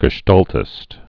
(gə-shtältĭst, -shtôl-, -stäl-, -stôl-)